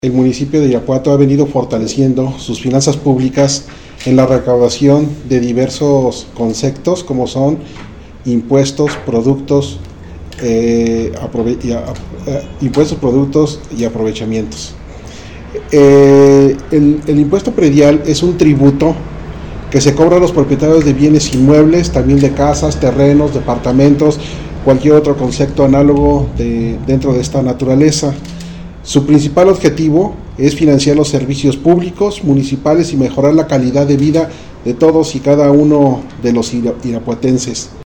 AudioBoletines
Miguel Ángel Fonseca Gutiéreez, tesorero